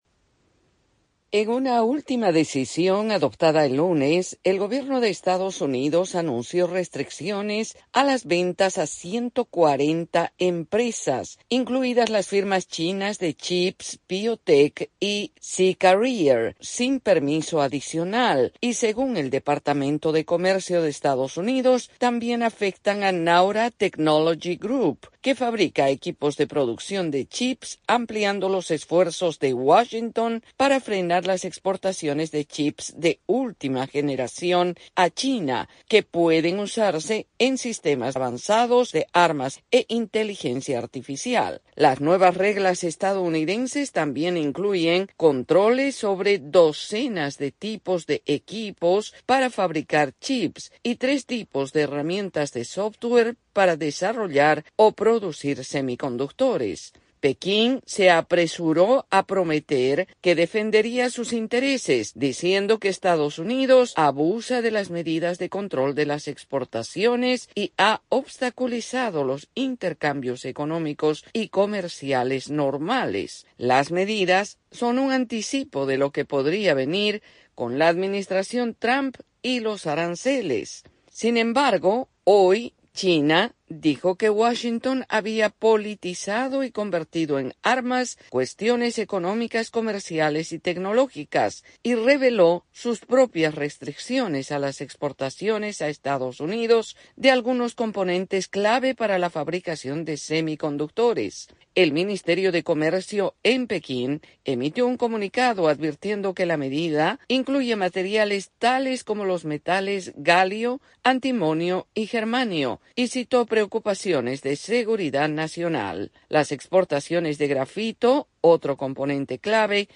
AudioNoticias
desde la Voz de América en Washington DC.